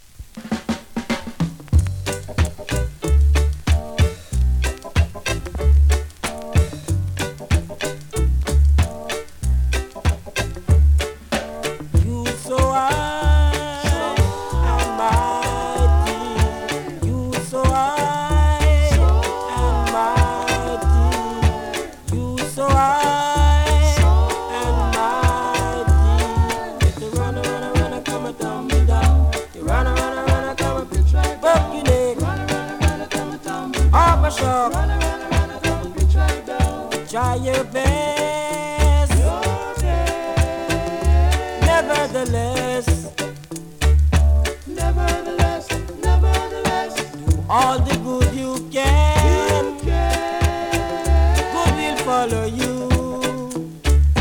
2026!! NEW IN!SKA〜REGGAE
スリキズ、ノイズかなり少なめの